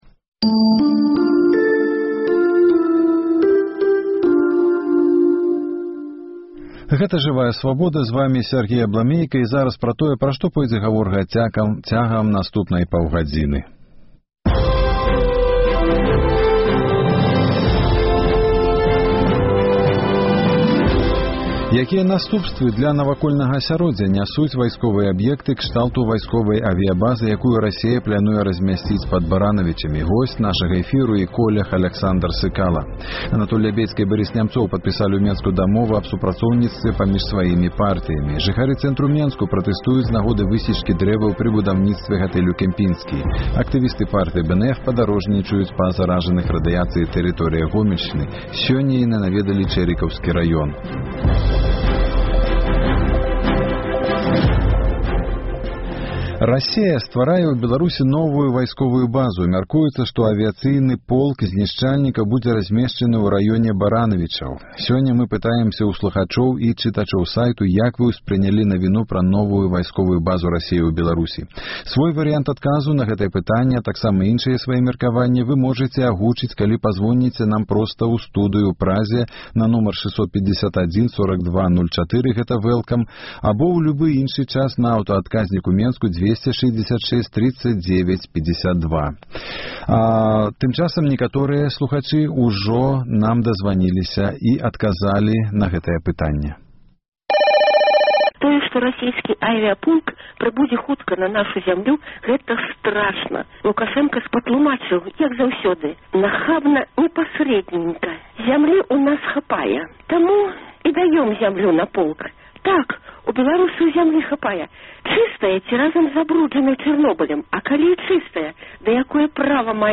Госьць эфіру — старшыня АГП Анатоль Лябедзька. Расея стварае ў Беларусі новую вайсковую базу.